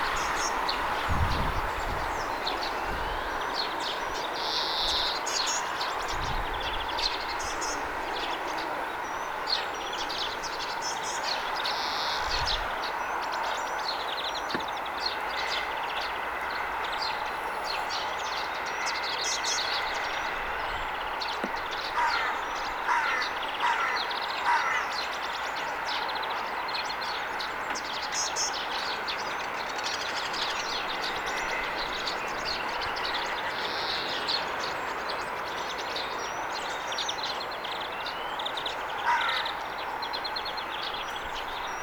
tuollaista sinitiaisen ääntelyä,
joka muistuttaa ihan sinitiaispoikasen ääniä
tuollaista_vahan_kuin_sinitiaisen_poikasen_aantelya.mp3